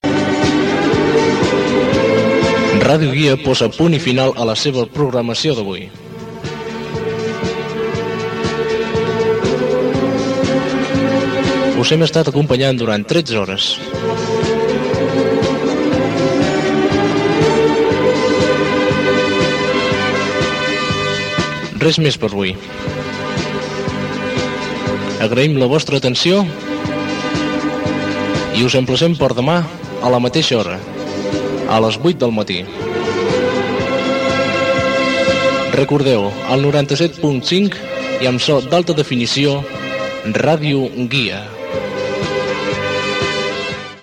Tancament d'emissió, amb identificació i freqüència.
FM